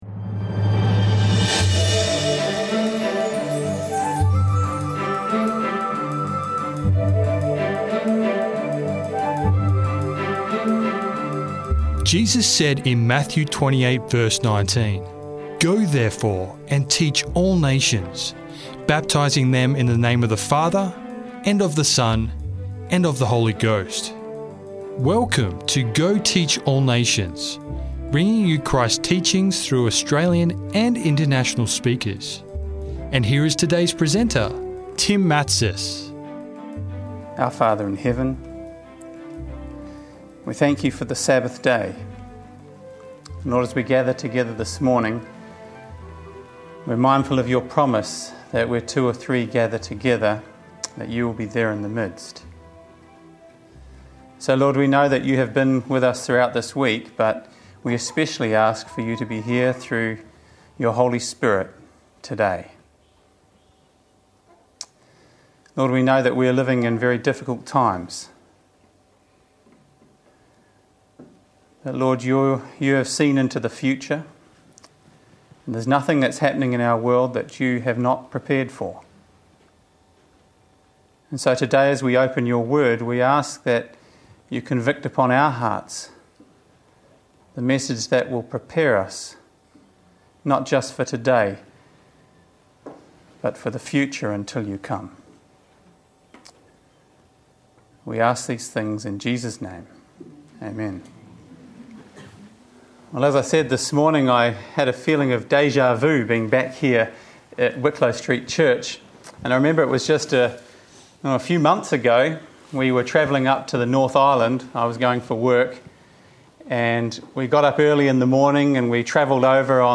Crossing the Jordan: Lessons in Faith and Perseverance - Sermon Audio 2547